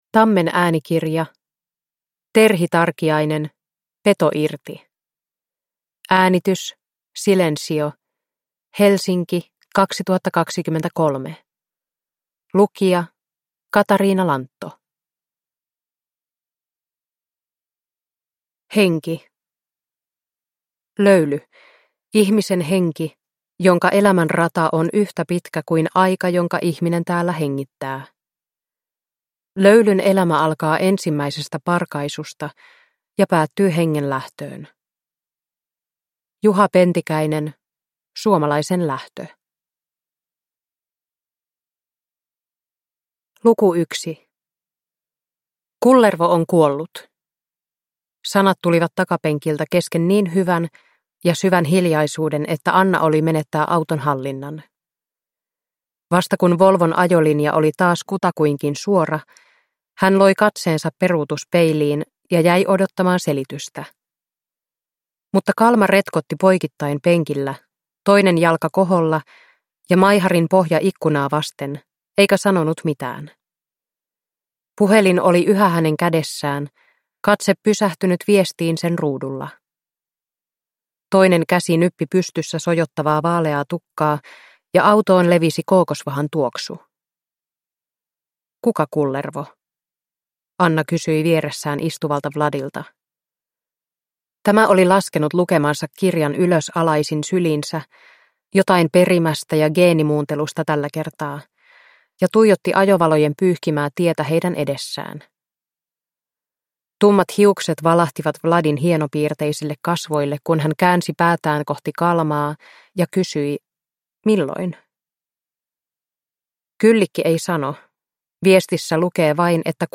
Peto irti – Ljudbok – Laddas ner